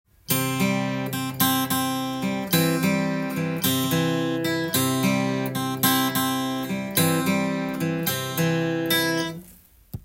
ピックと指弾き強化【ギターで16分音符のアルペジオ練習】
コードはCでアルペジオパターンを譜面にしてみました。
④～⑥はピック弾き＋中指の指弾きも出てくるので